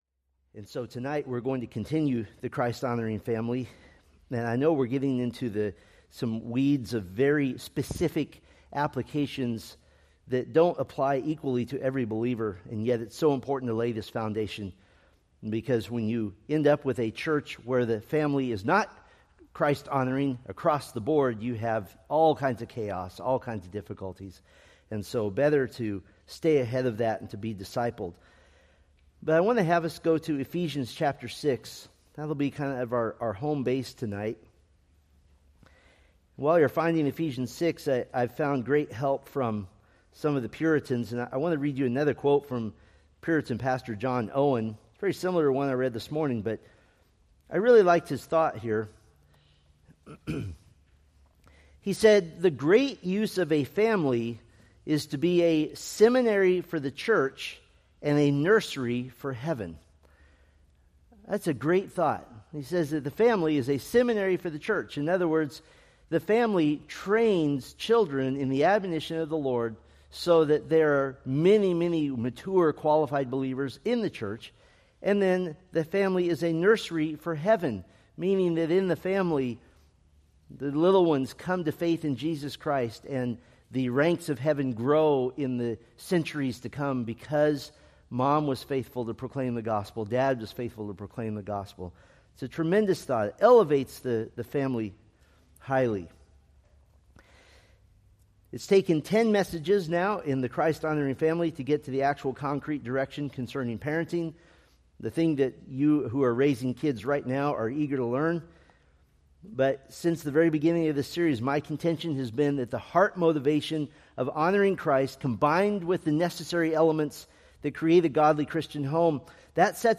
Preached August 17, 2025 from Selected Scriptures